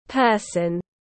Một người tiếng anh gọi là person, phiên âm tiếng anh đọc là /ˈpɜː.sən/.
Person /ˈpɜː.sən/